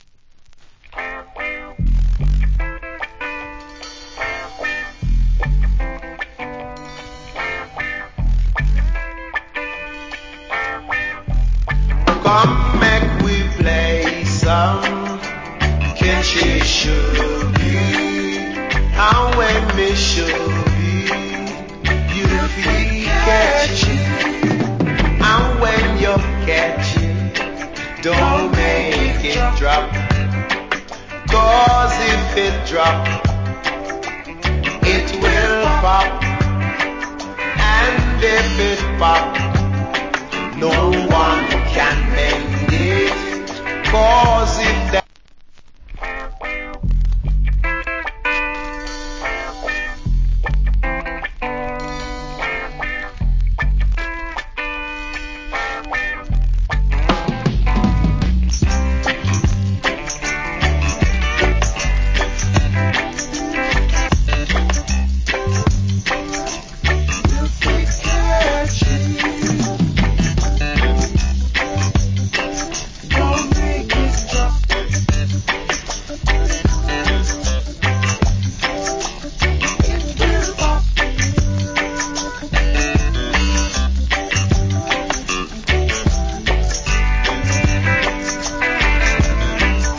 Roots Rock Vocal.